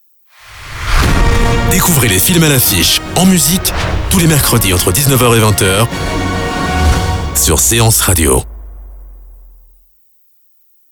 Voix-off grave jeune adulte / adulte
Kein Dialekt
Sprechprobe: Sonstiges (Muttersprache):